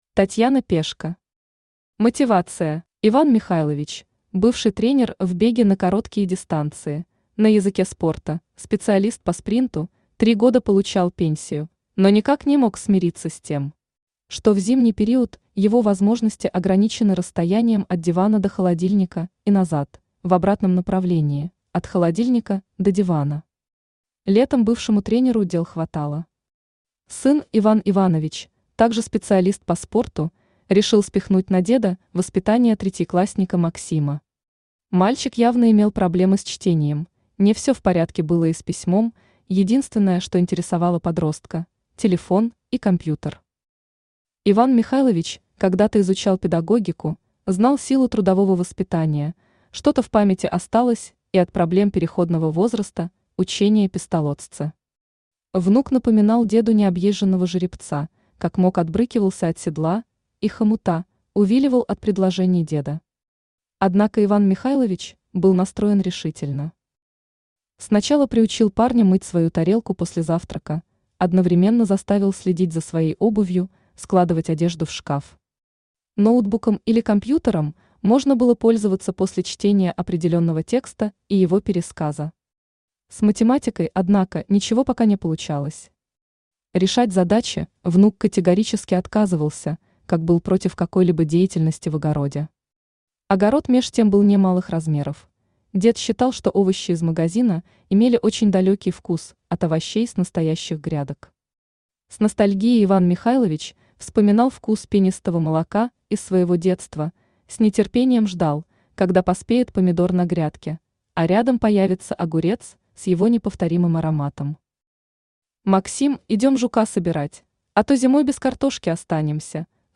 Аудиокнига Мотивация | Библиотека аудиокниг
Aудиокнига Мотивация Автор Татьяна Пешко Читает аудиокнигу Авточтец ЛитРес.